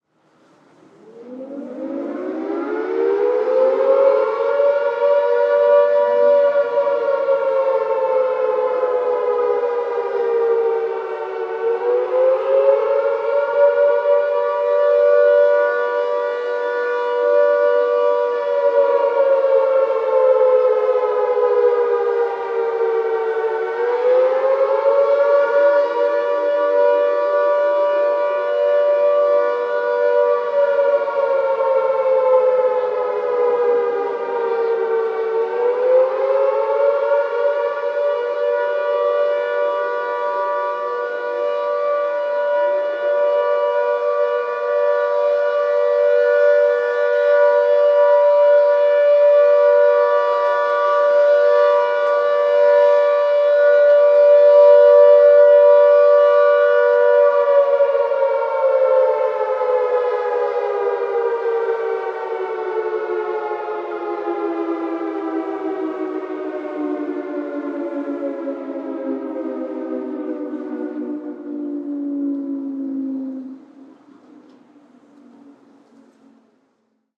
siren.ogg